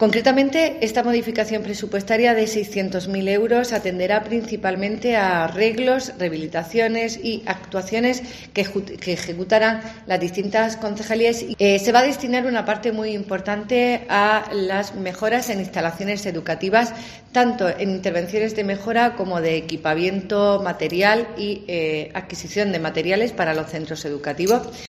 Belén Pérez, concejal Hacienda del Ayuntamiento de Lorca